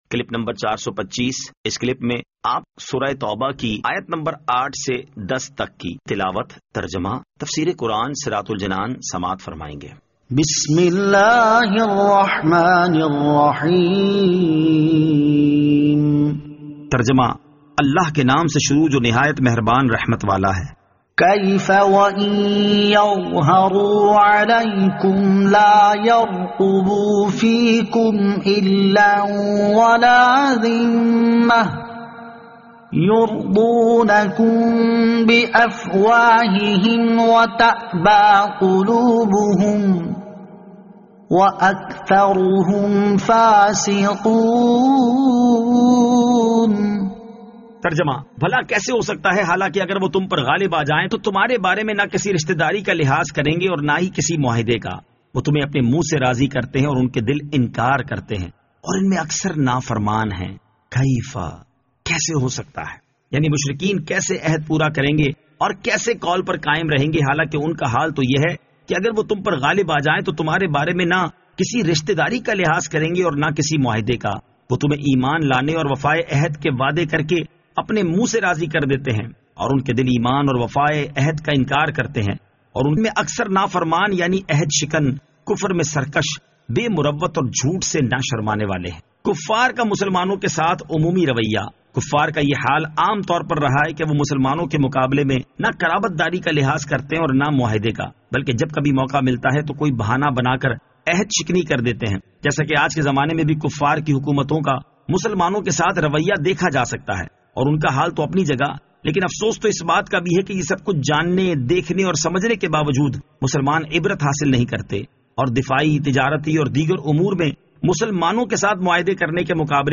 Surah At-Tawbah Ayat 08 To 10 Tilawat , Tarjama , Tafseer
2021 MP3 MP4 MP4 Share سُوَّرۃُ التَّوْبَۃ آیت 08 تا 10 تلاوت ، ترجمہ ، تفسیر ۔